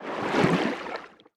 Sfx_creature_seamonkey_swim_fast_06.ogg